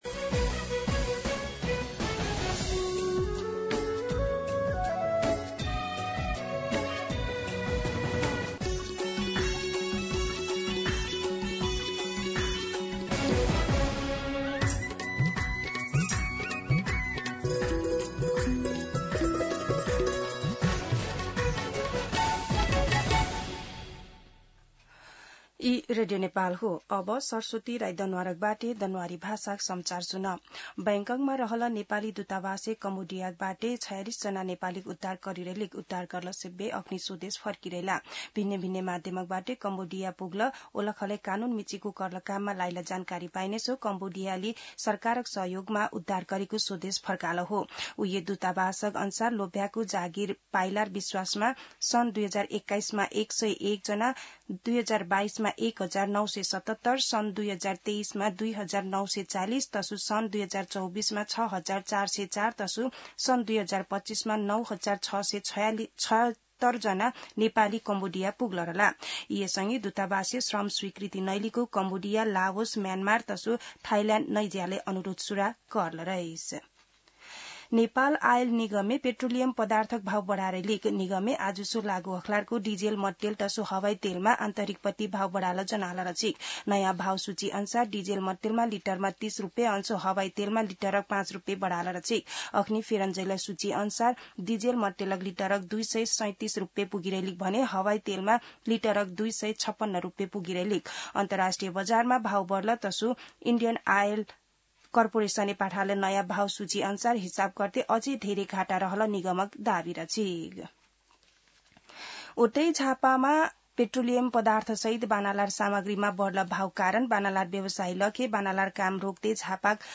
दनुवार भाषामा समाचार : ३ वैशाख , २०८३
Danuwar-News-1.mp3